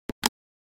Download Free Calculator Sound Effects | Gfx Sounds
Sharp-EL-8-old-calculator-single-button-press-4.mp3